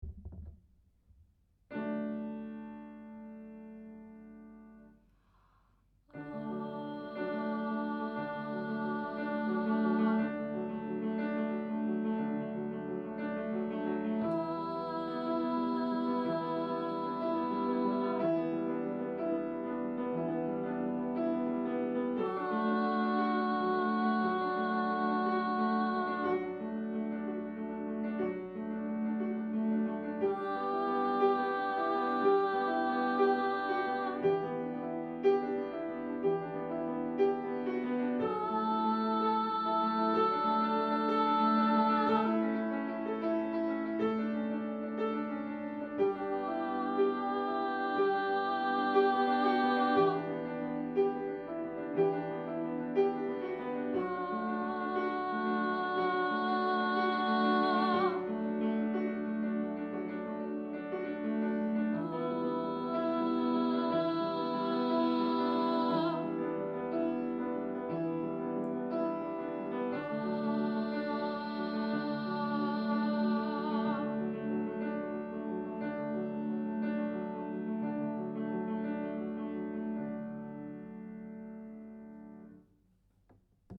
Long Notes Dmaj
A-Long-Notes-High.mp3